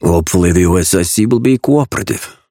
Raven voice line - Hopefully the OSIC will be cooperative.